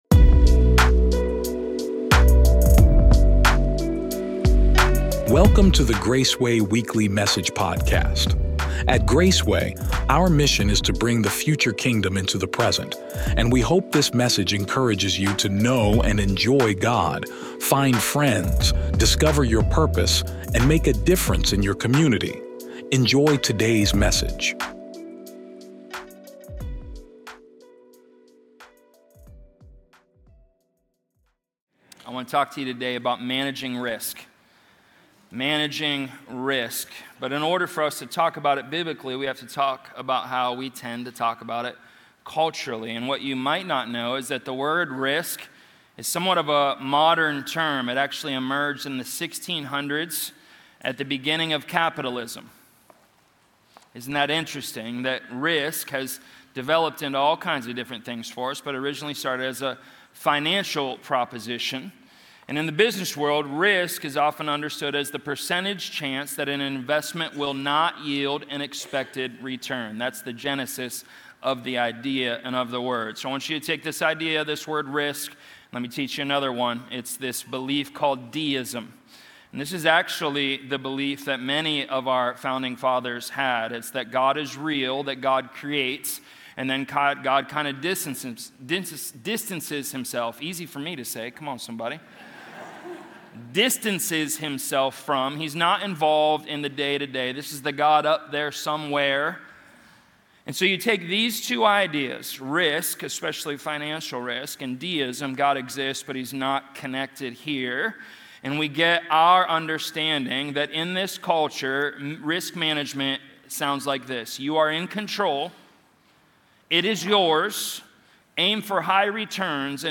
Drawing from Acts 5 and the bold example of the early church, this sermon challenges modern ideas of risk management and control, calling believers to active faith, courage, and radical obedience.